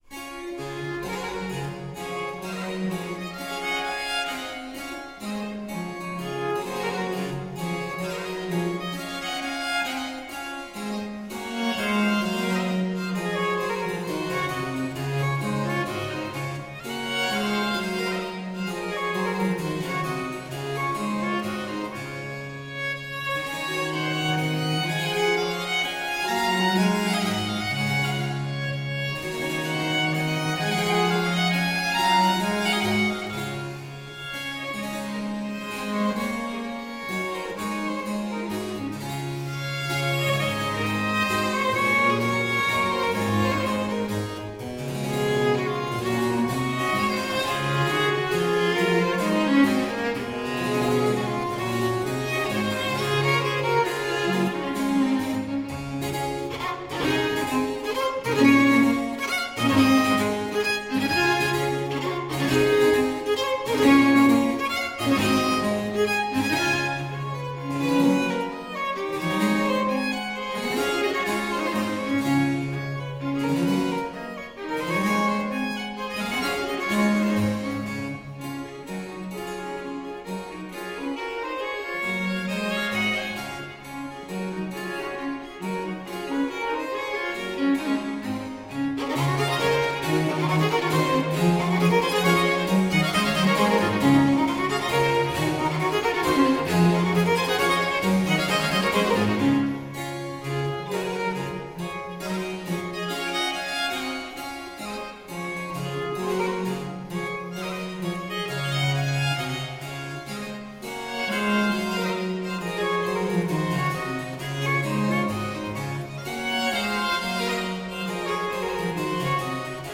Elegant and subdued